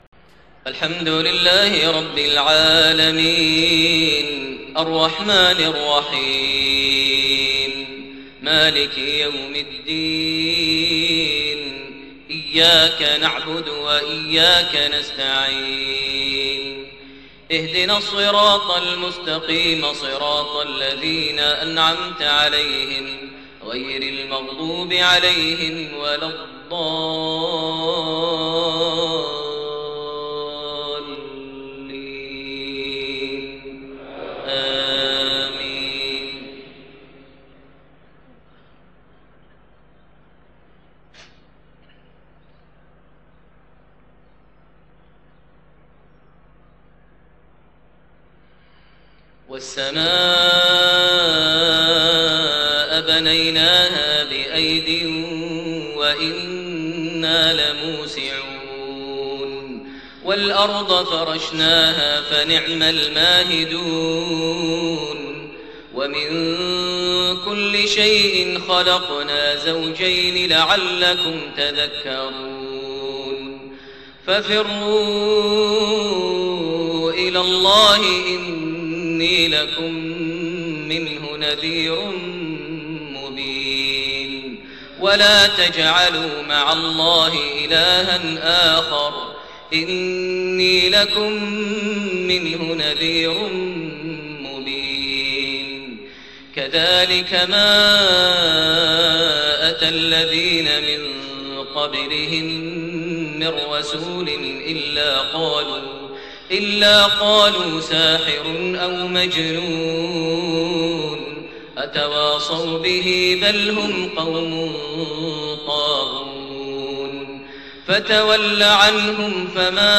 صلاة المغرب 6-5-1430 من سورة الذاريات47-60 > 1430 هـ > الفروض - تلاوات ماهر المعيقلي